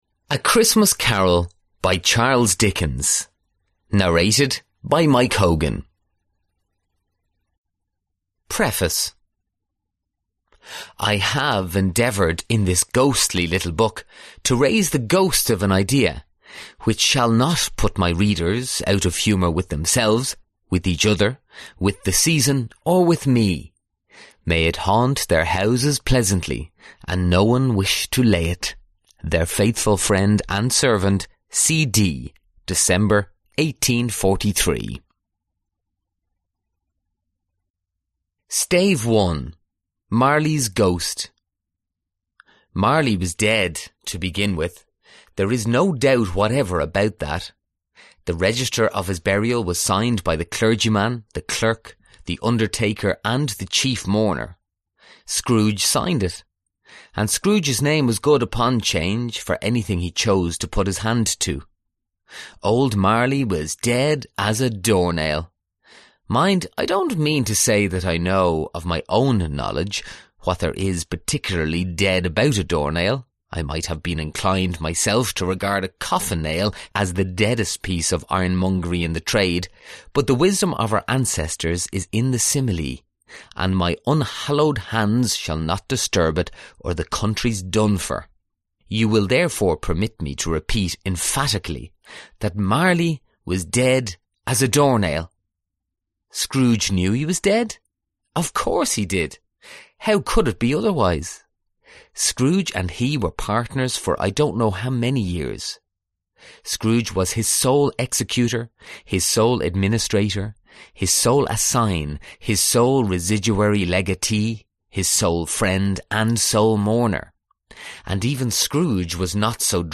Аудиокнига Classic Christmas Collection | Библиотека аудиокниг